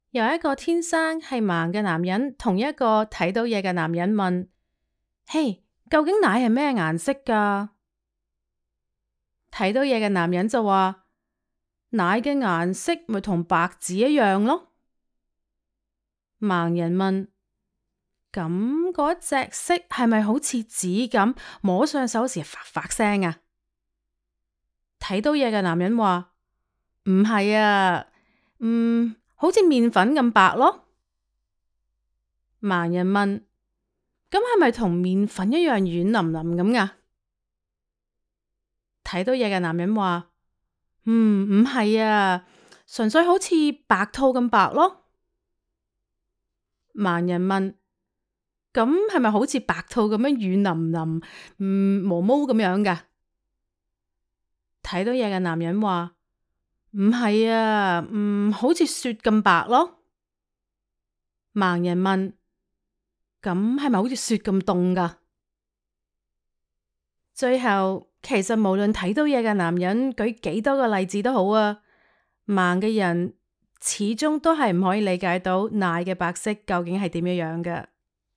Female
My voice has been described as friendly, trustworthy, convincing, authoritative, authentic, enthusiastic, enticing, cut from a different cloth with a sassy certainty by my clients and peers.
Studio Quality Sample Cantonese
1013studio_sample_canto.mp3